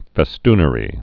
(fĕ-stnə-rē)